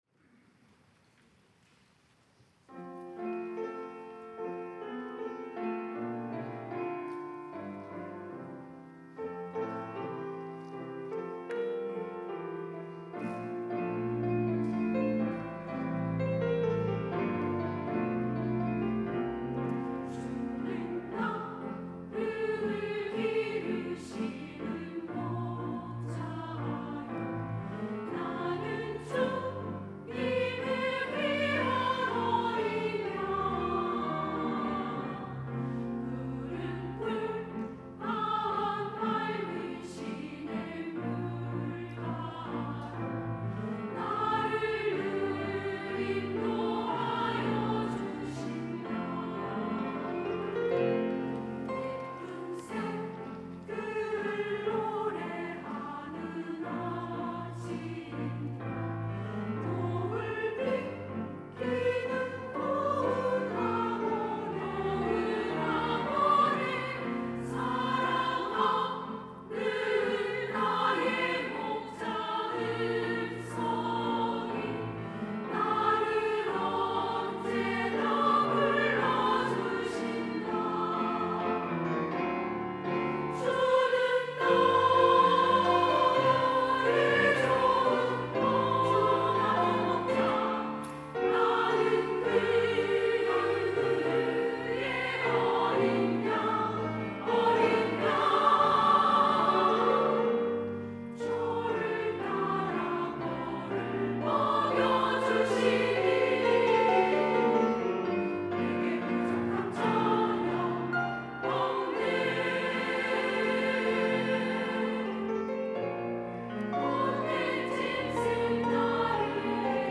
여전도회 - 주는 나를 기르시는 목자
찬양대